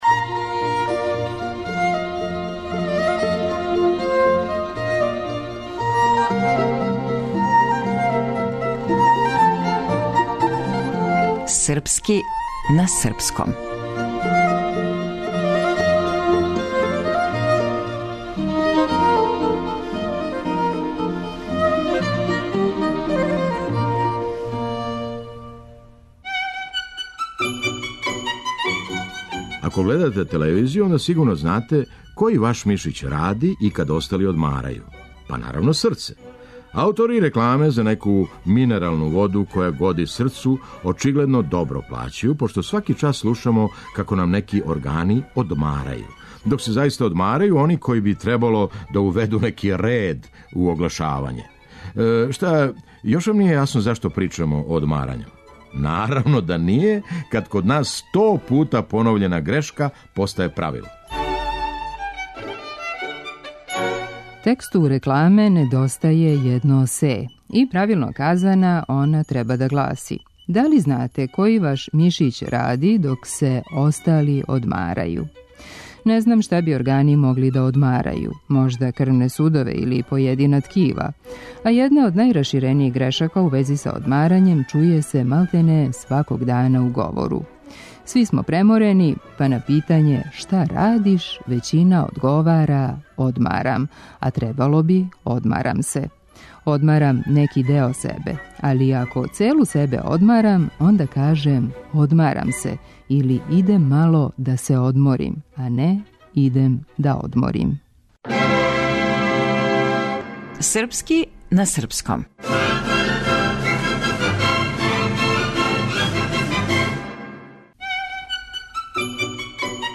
Драмски уметник - Феђа Стојановић